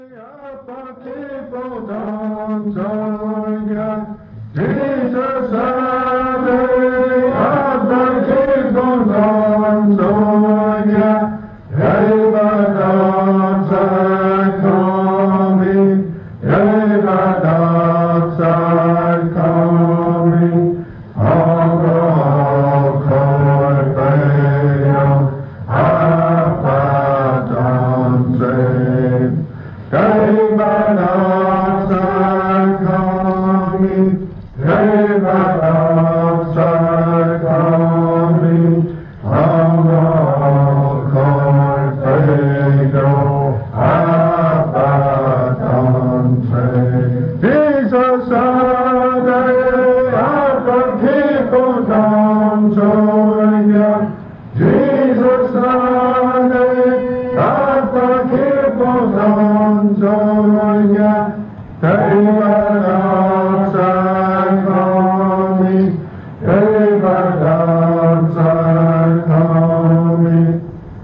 Church hymn